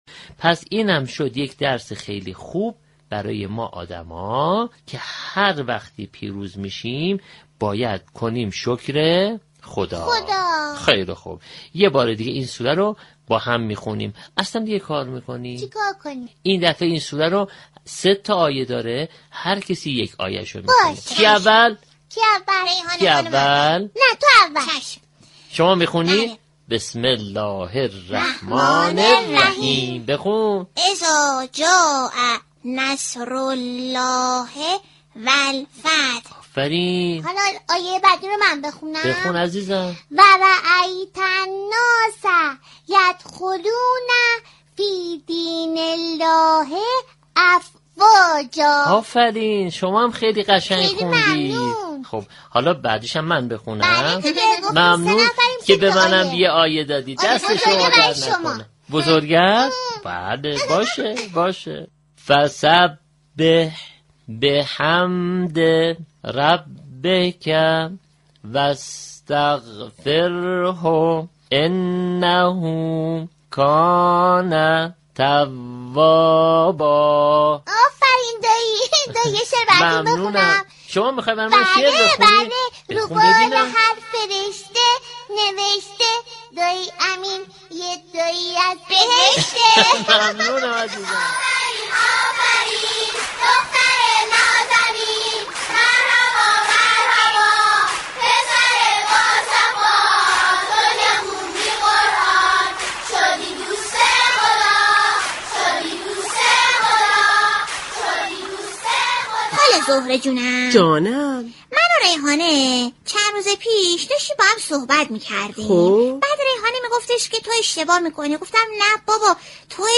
برنامه "صد دانه یاقوت" در قالب جنگی نمایشی